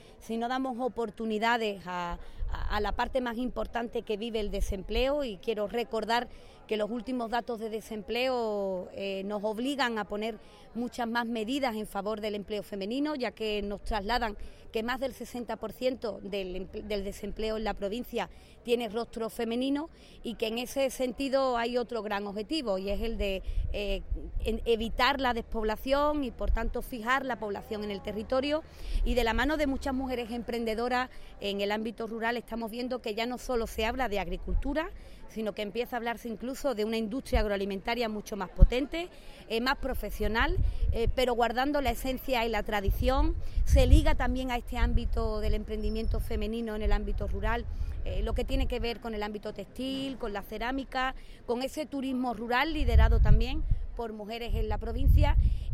La presidenta de la Diputación de Cádiz, Irene García, ha participado en la clausura del XVIII Encuentro Regional de Mujeres Emprendedoras del Medio Rural que se ha celebrado en Conil de la Frontera con organización de CERES Andalucía.
Irene-Garcia-jornada-Ceres.mp3